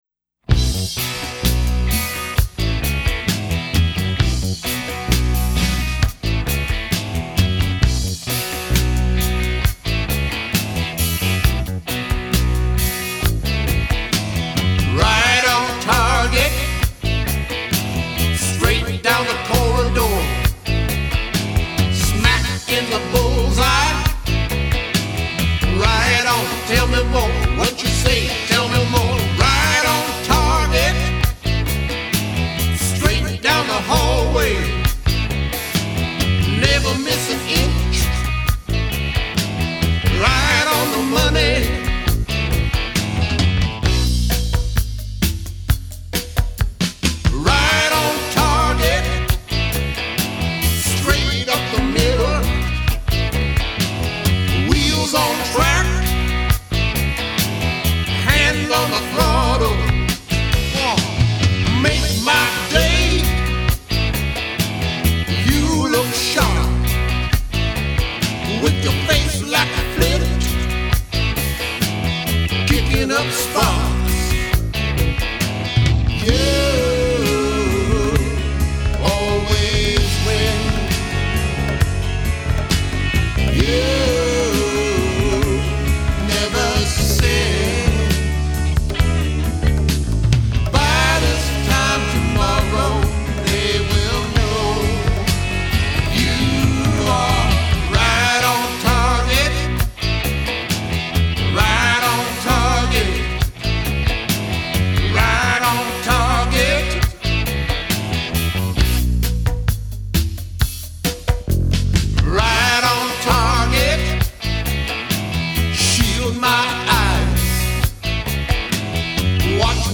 Genres: Alternative/Indie Rock, Indie Pop